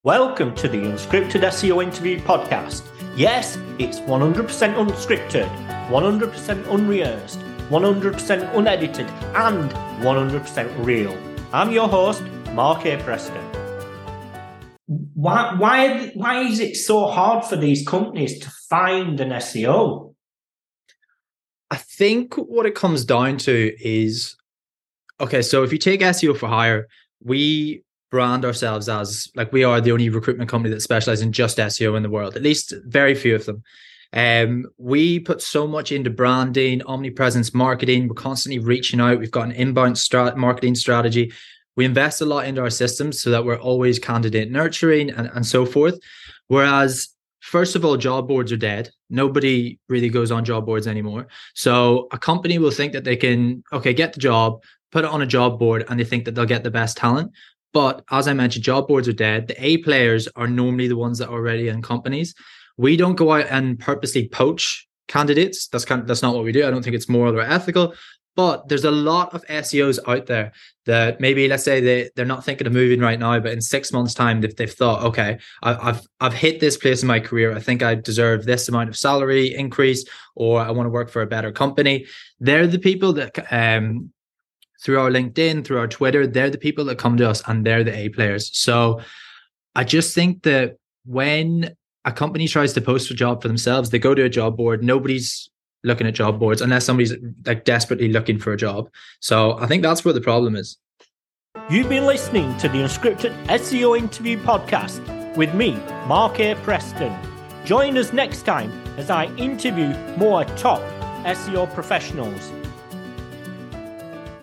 This conversation is a beacon for companies looking to innovate their recruitment strategies and for SEO professionals aiming to understand the evolving dynamics of their industry.